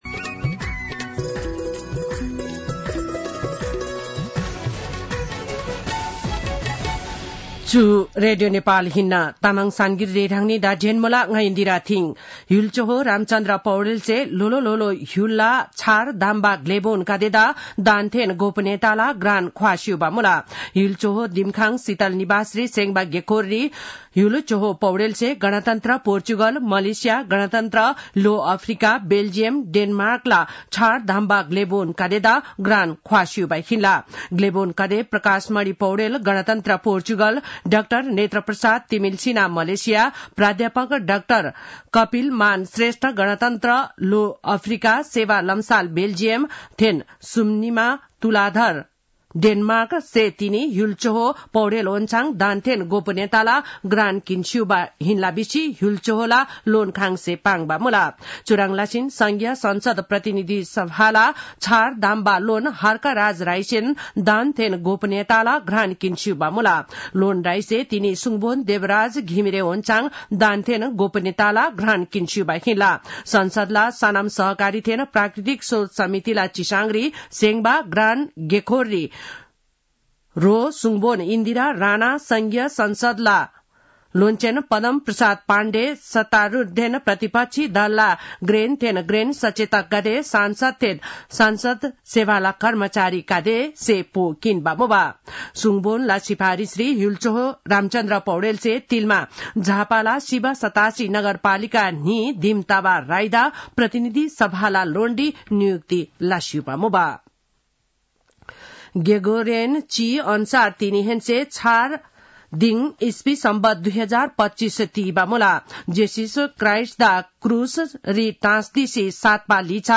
तामाङ भाषाको समाचार : १८ पुष , २०८१
Tamang-News-9-17.mp3